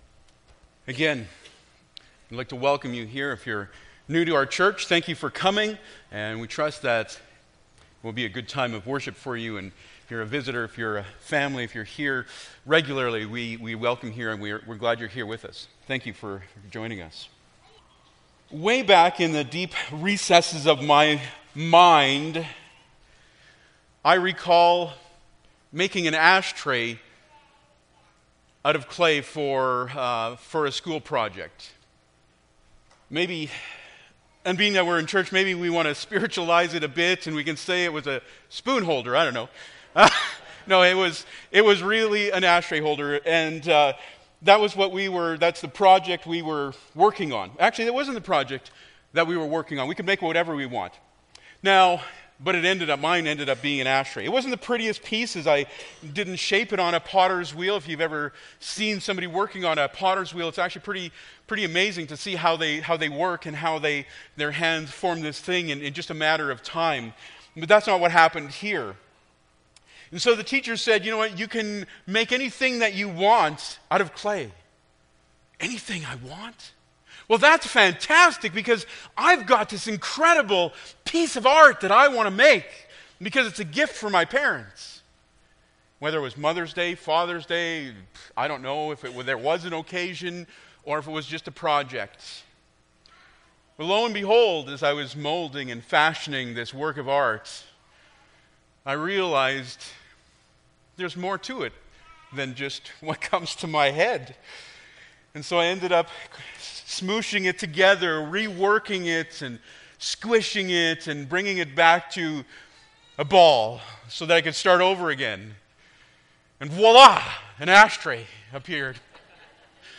Jeremiah 18:1-12 Service Type: Sunday Morning Bible Text